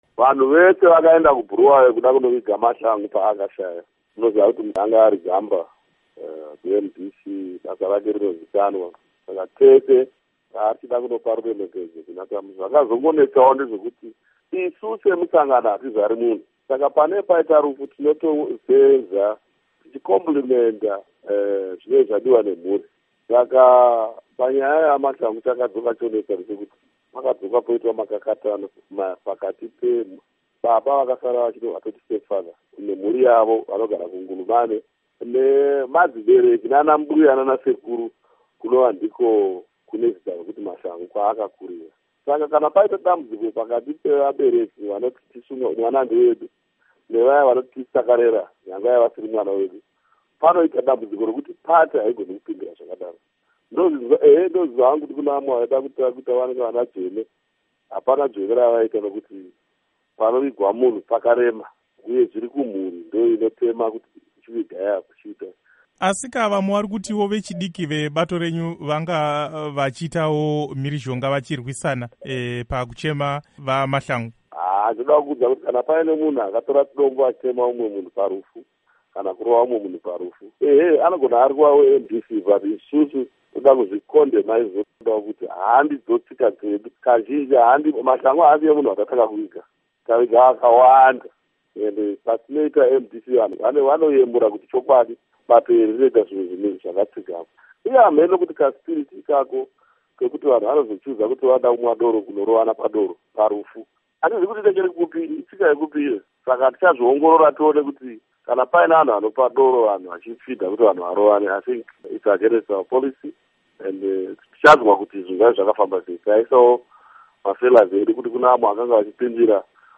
Hurukuro naVaMorgan Tsvangirai